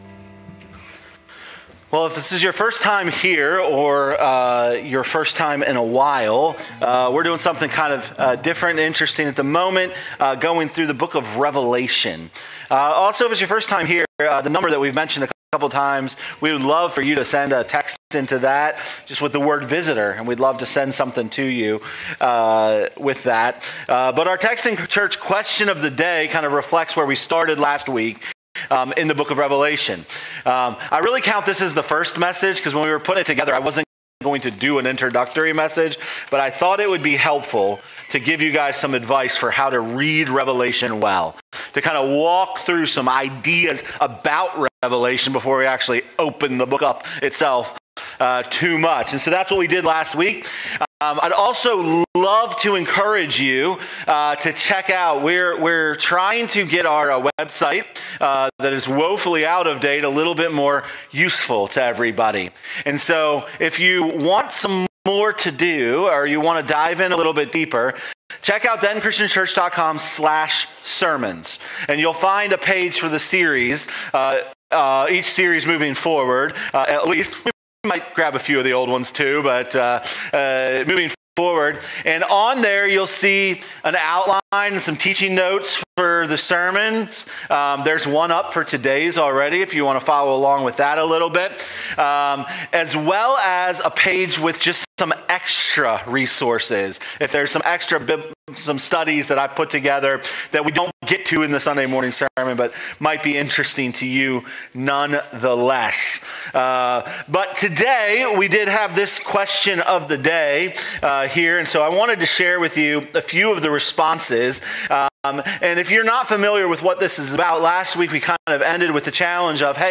All Sermons , Revealed Book Revelation Watch Listen Save teaching Outline Slides Discussion Questions The images of Christ in Revelation reveal significant truths about who Jesus is and His mission in the world.